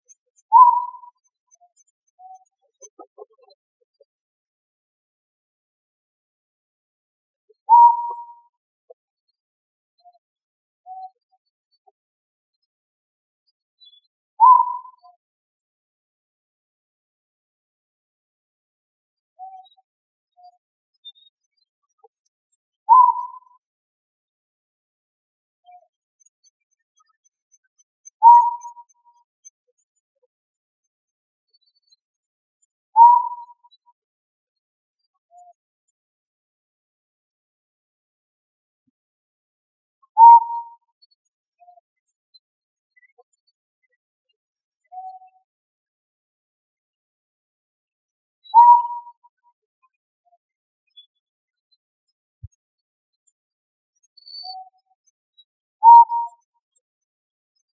Mp3 Burung Hantu Suara Jernih
Berikut ini kumpulan dari mp3 burung hantu yang ada di seluruh penjuru dunia.
> Otus manadensis 2